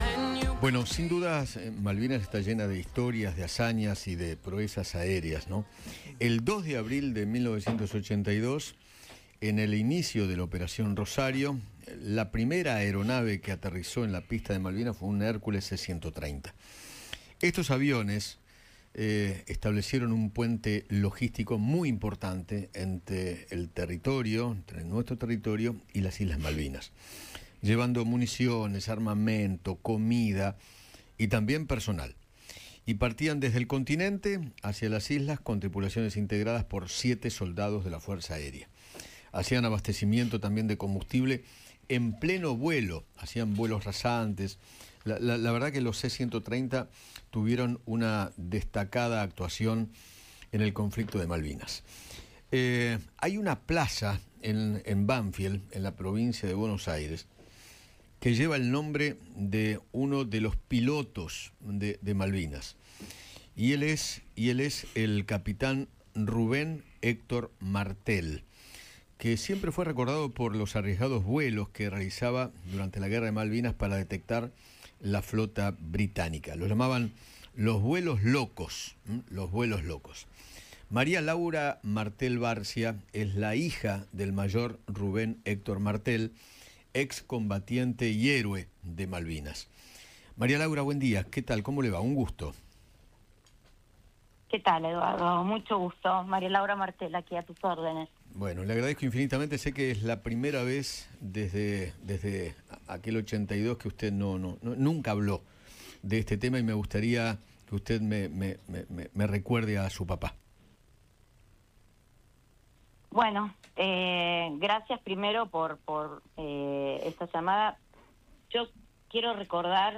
conversó con Eduardo Feinmann sobre la historia de su padre